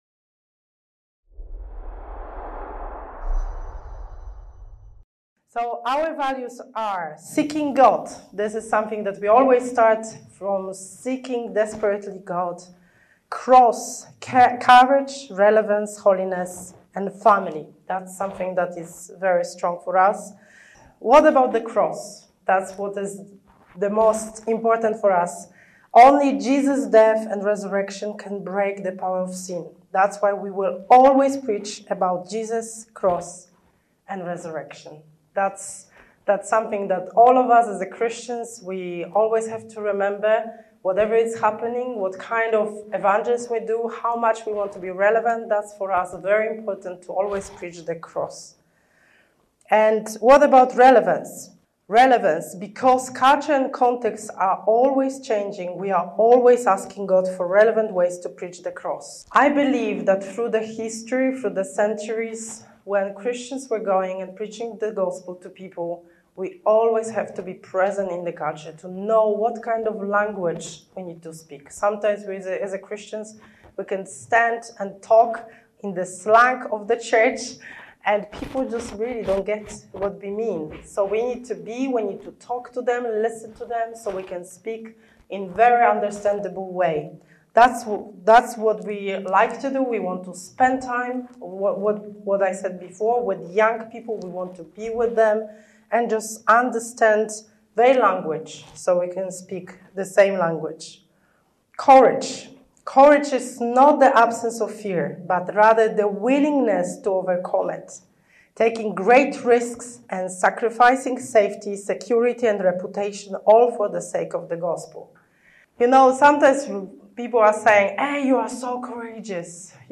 This talk teaches principles and models for relevantly sharing Jesus and making disciples among young people who would not usually walk into your church. How can we communicate the Gospel to them when they have such a negative view of God?